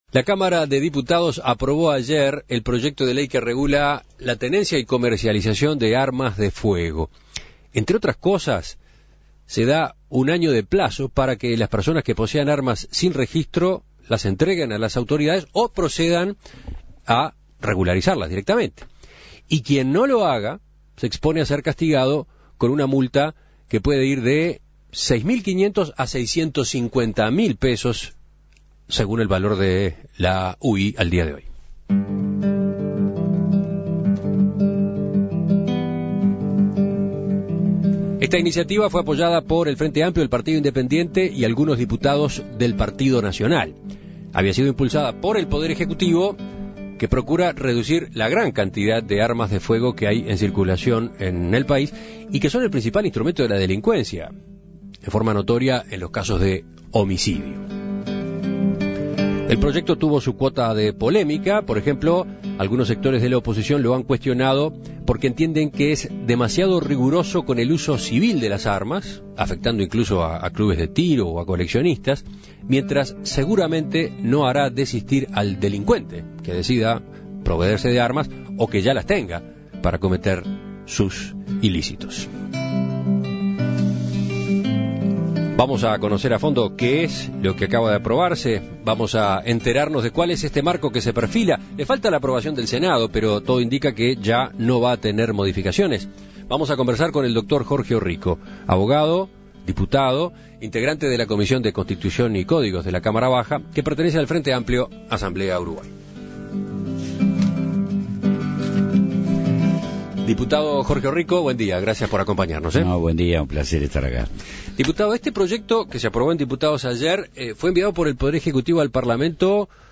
Escuche la entrevista a Jorge Orrico